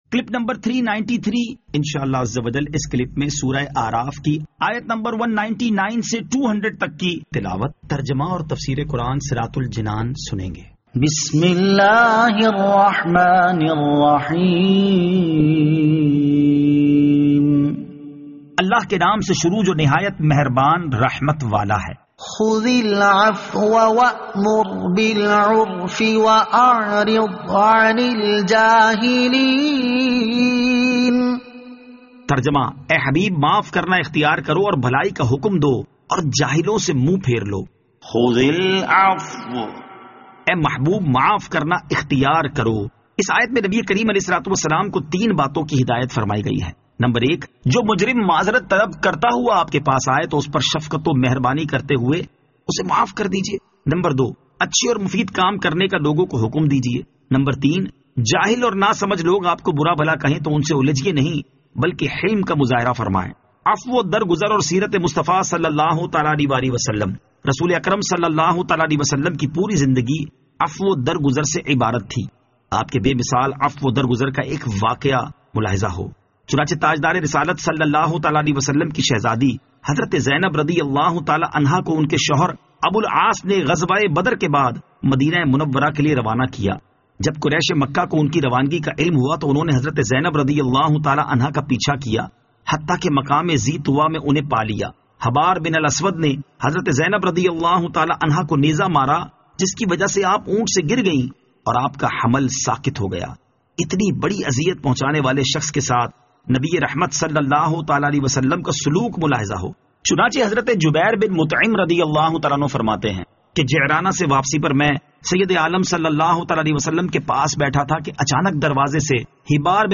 Surah Al-A'raf Ayat 199 To 200 Tilawat , Tarjama , Tafseer